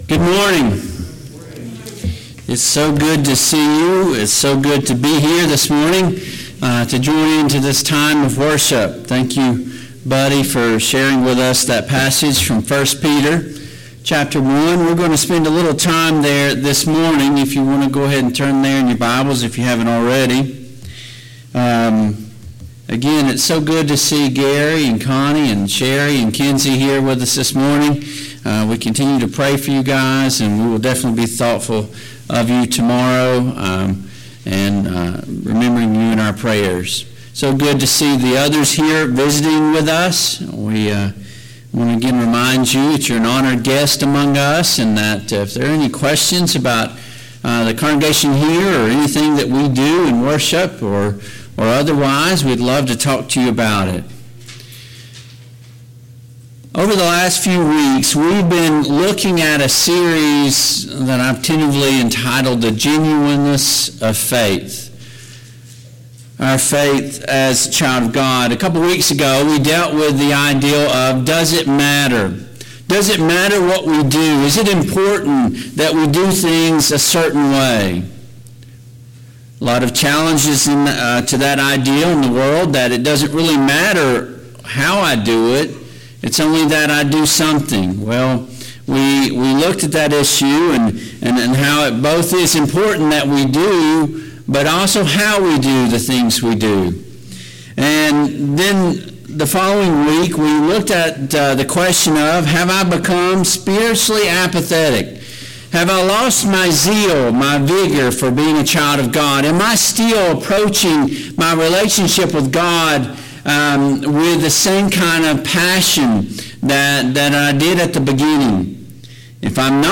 Passage: I Peter 1:3-9 Service Type: AM Worship Topics